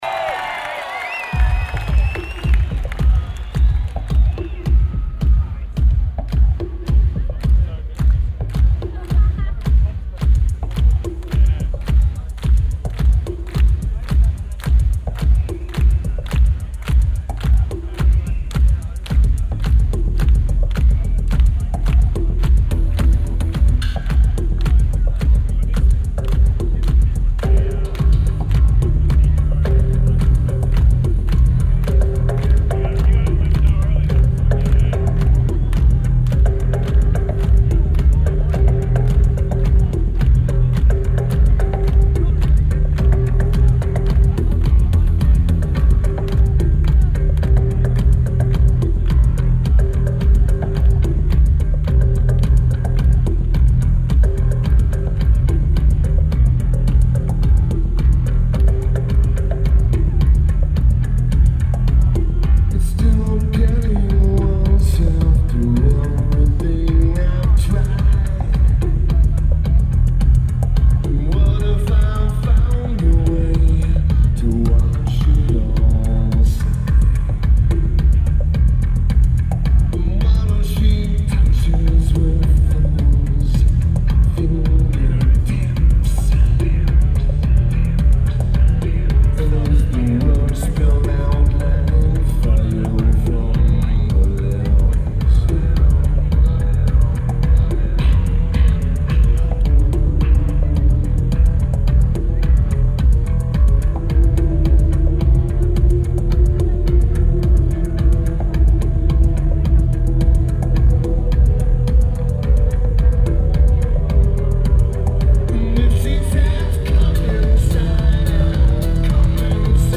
Rod Laver Arena
Keyboards/Bass/Backing Vocals
Drums
Guitar
Vocals/Guitar/Keyboards
Lineage: Audio - AUD (Soundman OKM II Rock + Sony PCM-M10)
Very good recording, especially for a first recording!